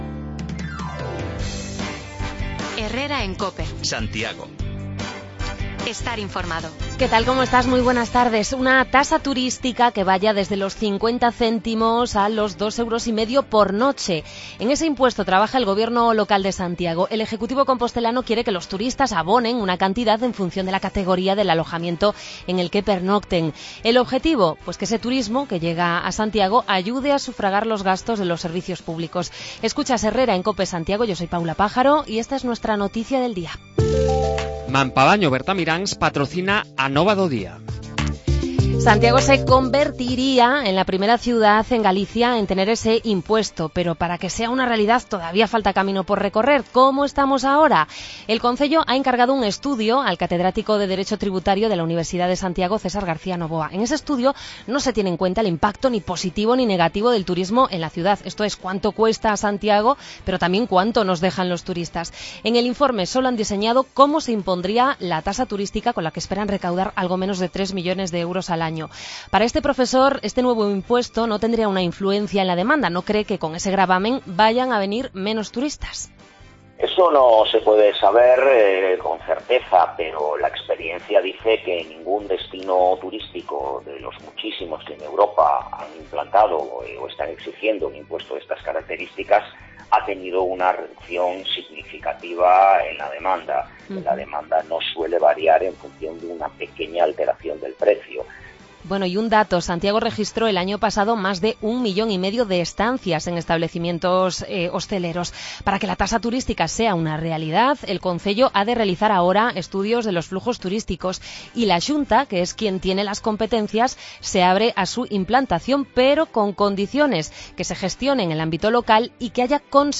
Hablamos sobre la tasa turística que quiere implantar el concello de Santiago: recogemos opiniones entre viajeros que llegaban hoy a la estación compostelana.